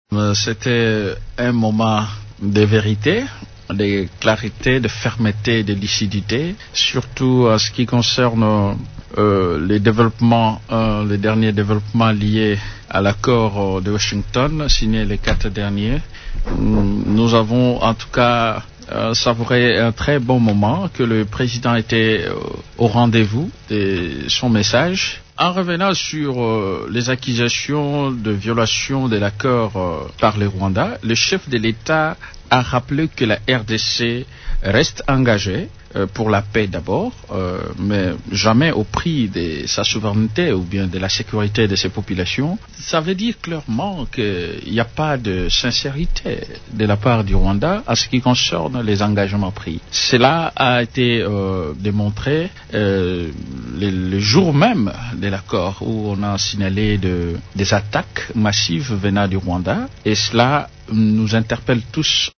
Intervenant au cours de l’émission Dialogue entre Congolais de lundi 8 décembre soir, Yannick Mambula a salué particulièrement le fait que le chef de l’État ait dénoncé la violation de l’accord de Washington par le Rwanda, survenue quelques jours seulement après sa signature.
A ce sujet, le député Yannick Mambu estime qu’il n’y a pas sincérité de la part du Rwanda :